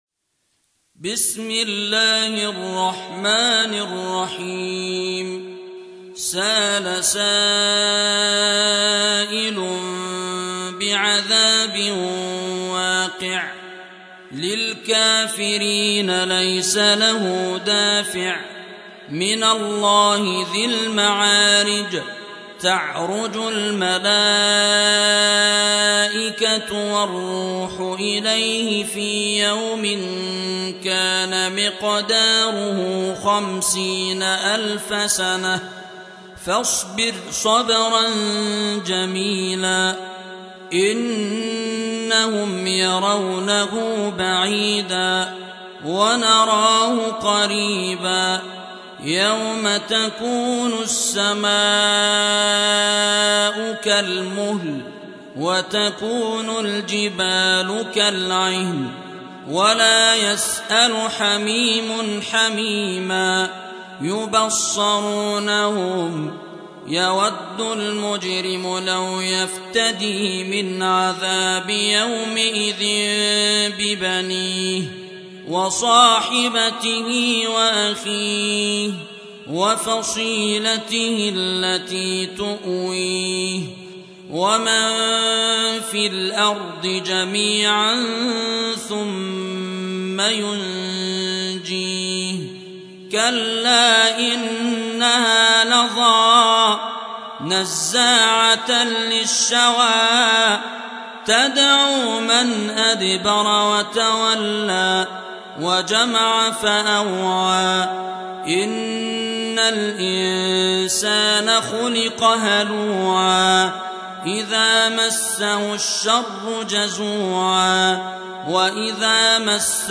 Surah Repeating تكرار السورة Download Surah حمّل السورة Reciting Murattalah Audio for 70. Surah Al-Ma'�rij سورة المعارج N.B *Surah Includes Al-Basmalah Reciters Sequents تتابع التلاوات Reciters Repeats تكرار التلاوات